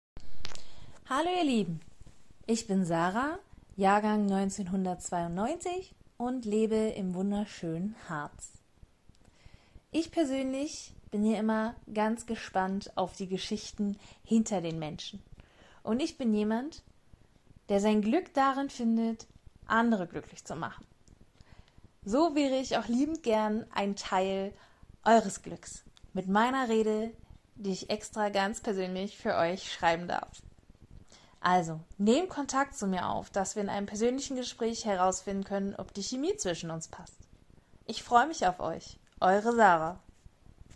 Stimmprobe
Stimmprobe-1-1.mp3